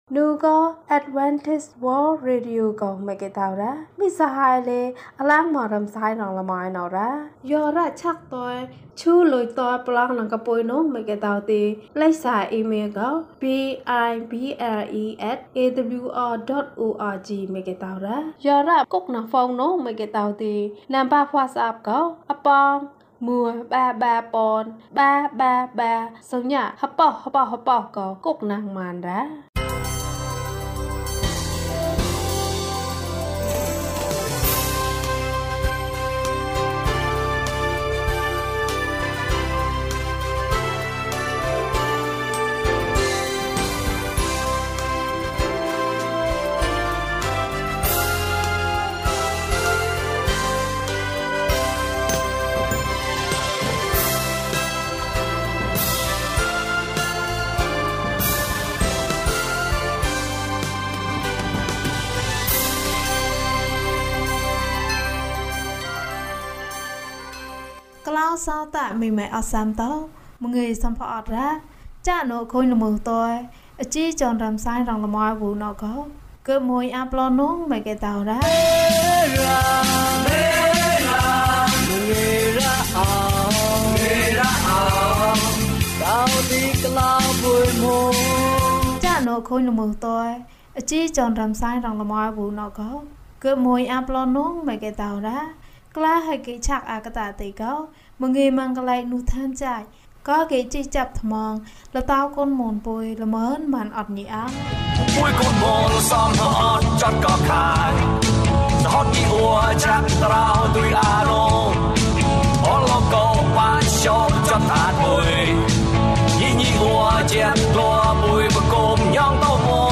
သင်၏ဆုတောင်းသံကို ဘုရားသခင်ကြားတော်မူ၏။ ကျန်းမာခြင်းအကြောင်းအရာ။ ဓမ္မသီချင်း။ တရားဒေသနာ။